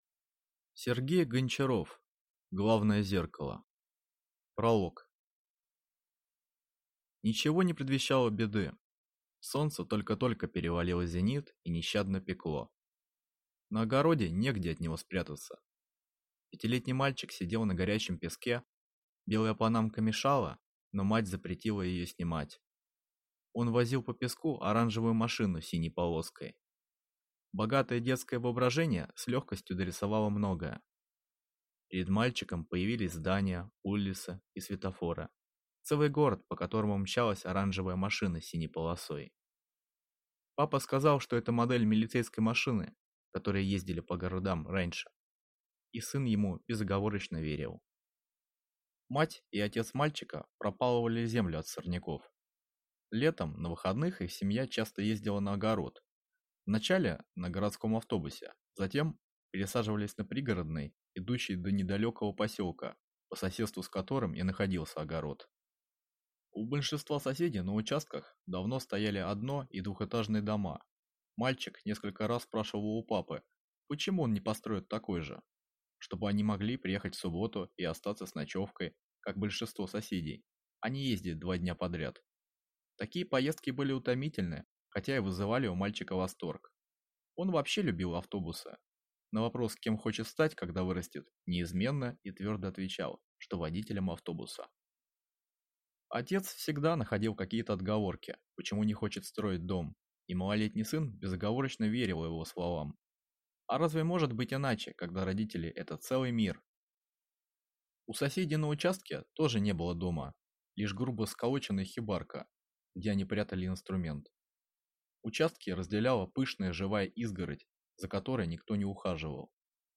Аудиокнига Главное зеркало | Библиотека аудиокниг